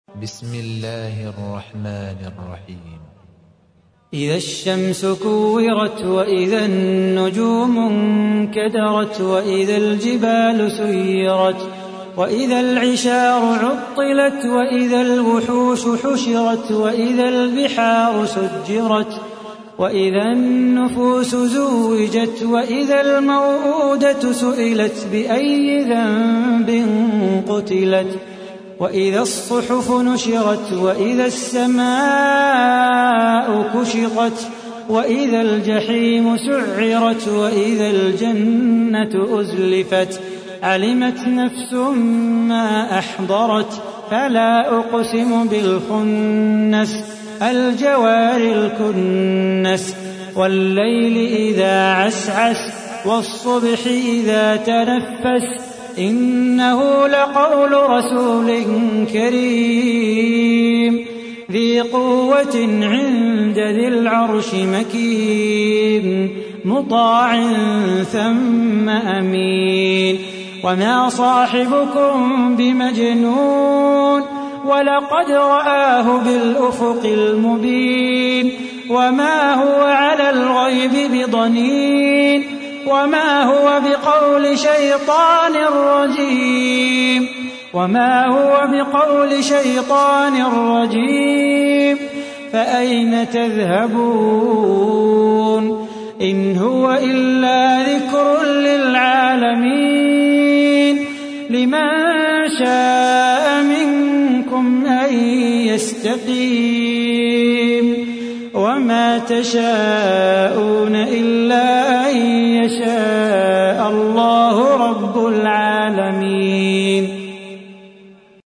تحميل : 81. سورة التكوير / القارئ صلاح بو خاطر / القرآن الكريم / موقع يا حسين